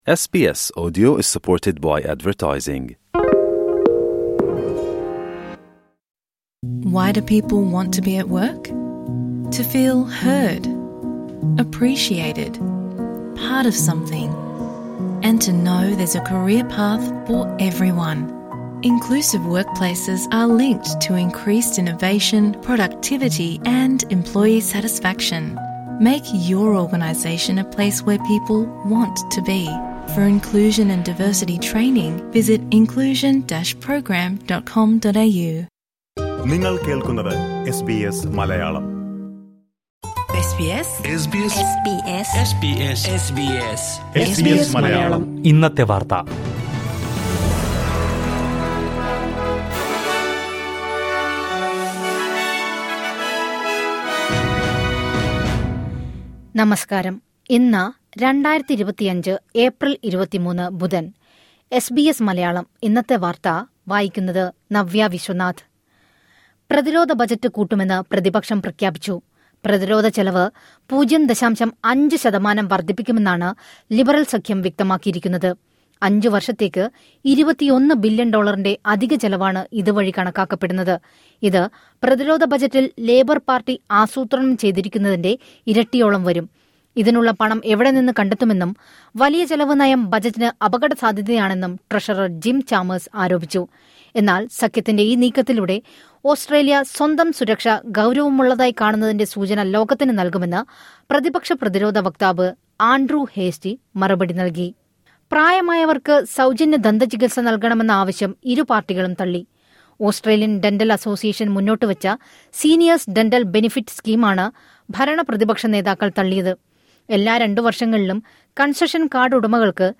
2025 ഏപ്രില്‍ 23ലെ ഓസ്‌ട്രേലിയയിലെ ഏറ്റവും പ്രധാന വാര്‍ത്തകള്‍ കേള്‍ക്കാം...